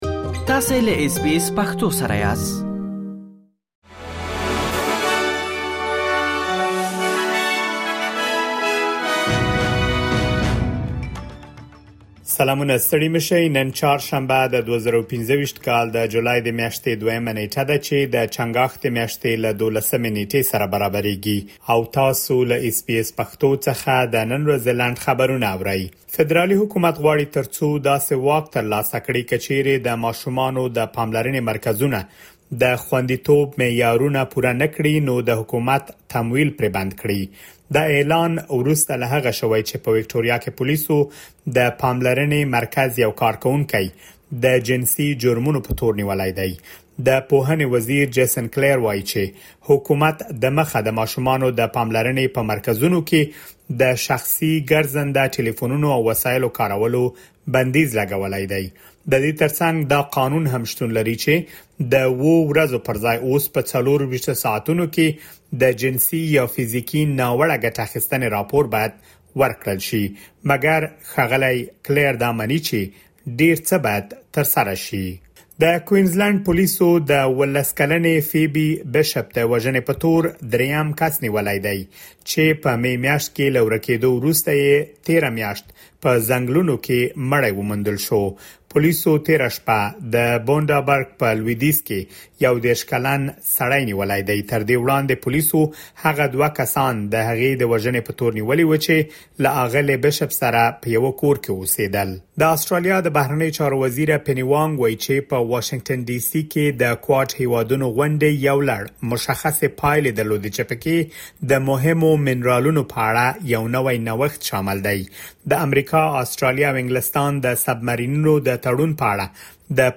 د اس بي اس پښتو د نن ورځې لنډ خبرونه |۲ جولای ۲۰۲۵